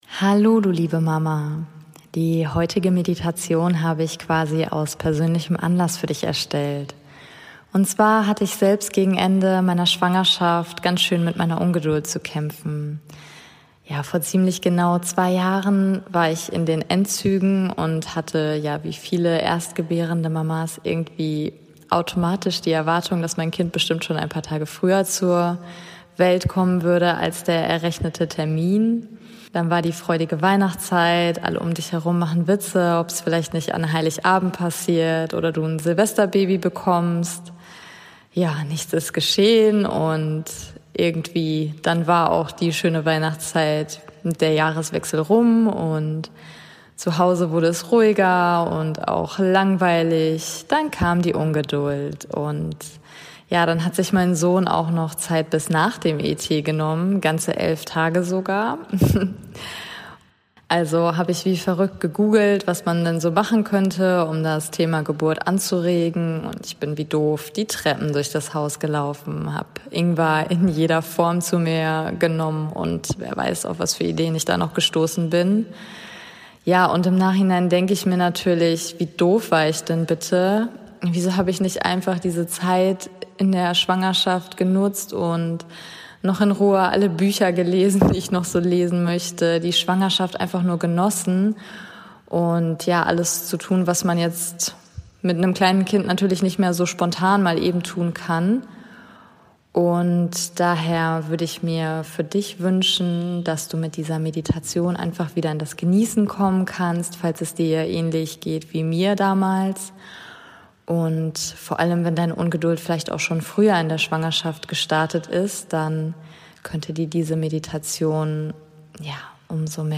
#007 - Meditation Übe Geduld während du auf dein Baby wartest ~ Meditationen für die Schwangerschaft und Geburt - mama.namaste Podcast